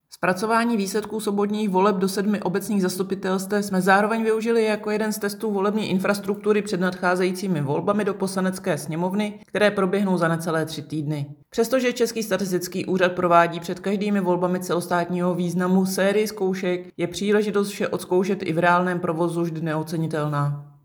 Vyjádření Evy Krumpové, 1. místopředsedkyně Českého statistického úřadu, soubor ve formátu MP3, 817.5 kB